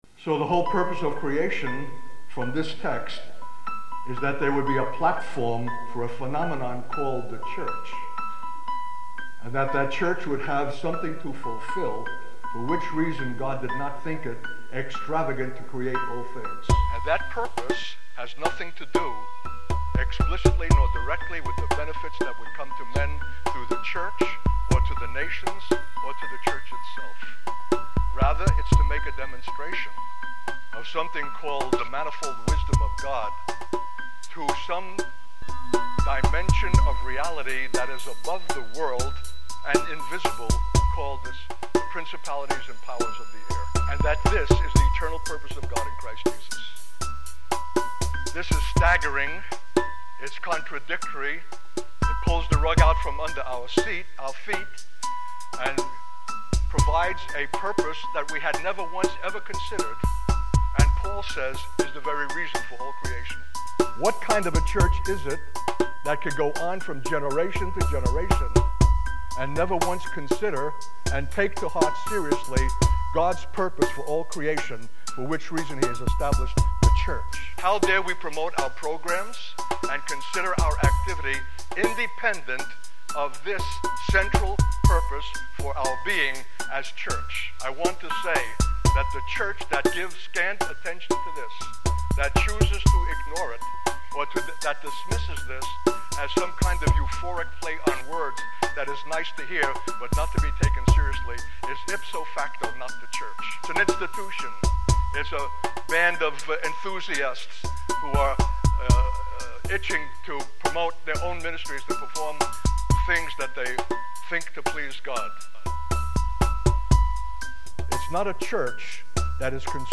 In this sermon, the speaker emphasizes the importance of the church in fulfilling God's eternal purpose.